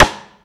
Snare 04.wav